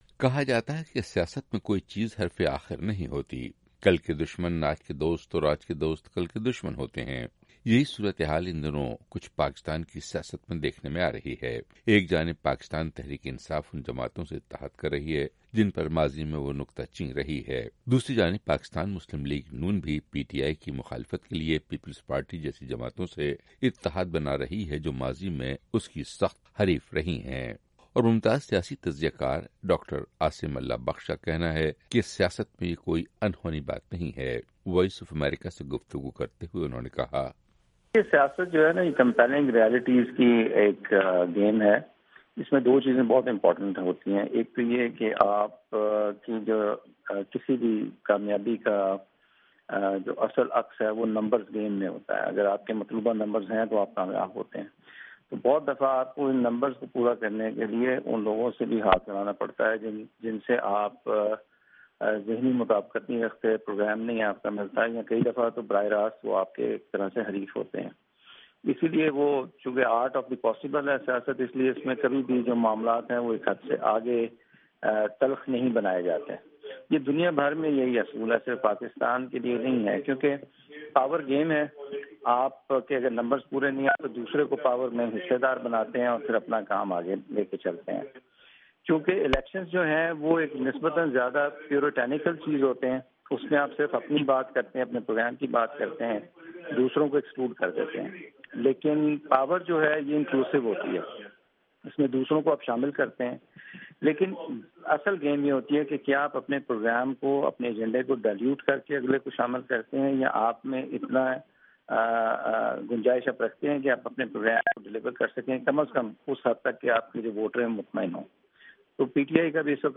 رپورٹ